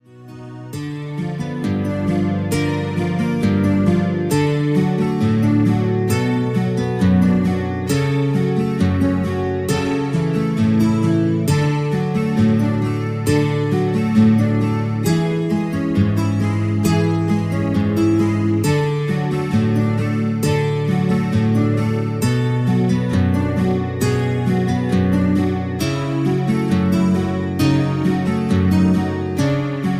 Backing track files: 1960s (842)